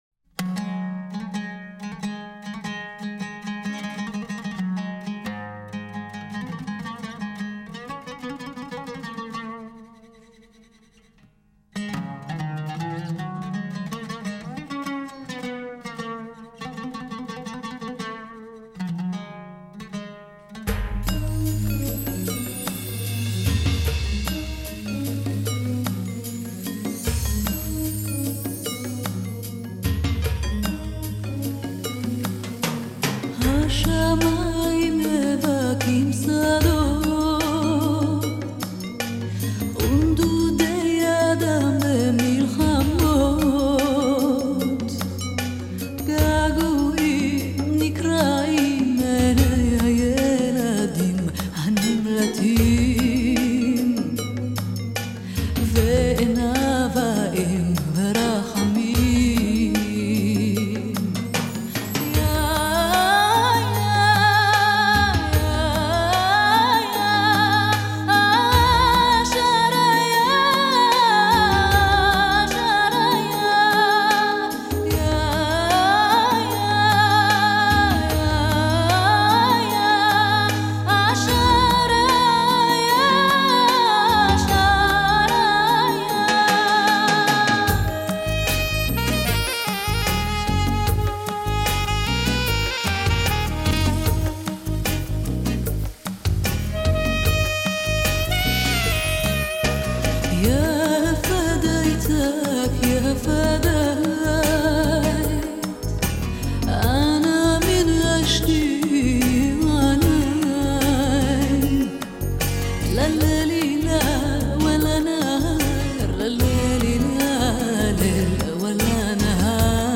Here’s a beautiful vocal, on the ‘softer’ side